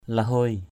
/la-hʊoɪ/ (d.) mồi = amorce. bait. lahuai wah l=h& wH mồi cá, mồi câu = amorce pour la pêche. bait for fishing.